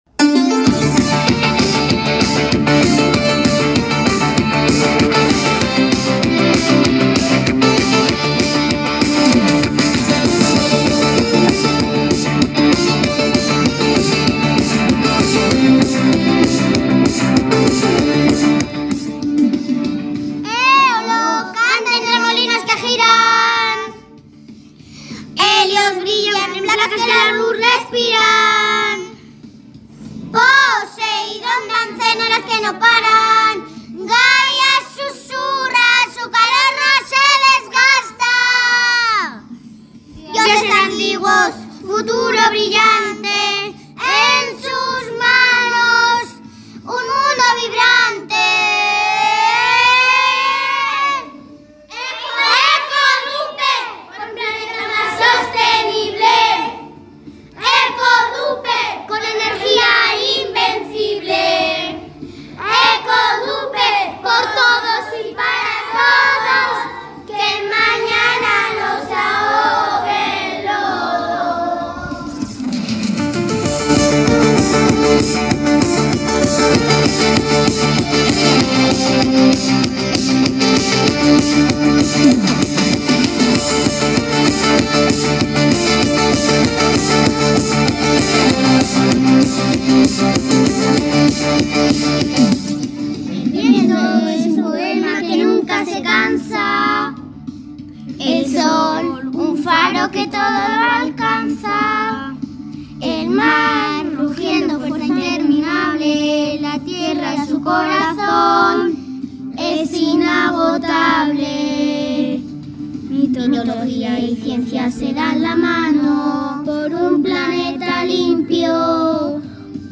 CANCIONES PARA EL CARNAVAL
- Para el baile de alumnado.